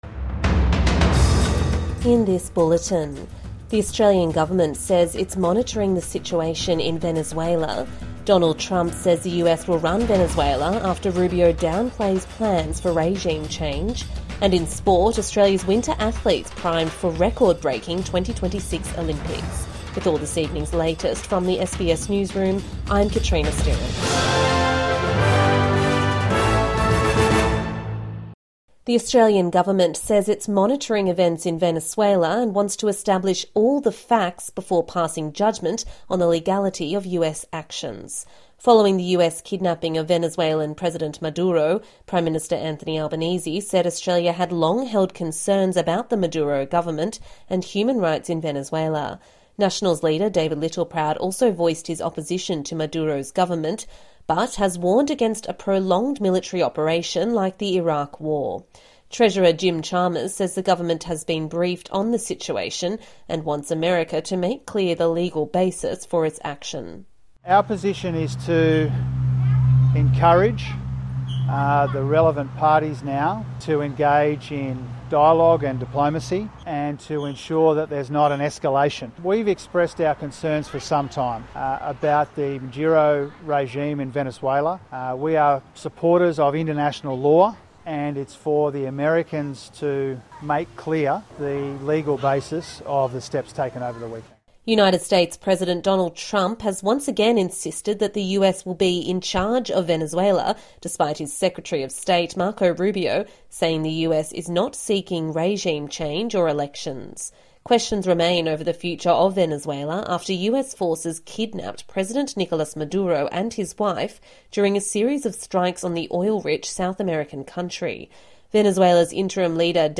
Australian government says it's monitoring situation in Venezuela | Evening News Bulletin 5 January 2026